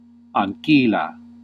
Ääntäminen
IPA : /meɪd/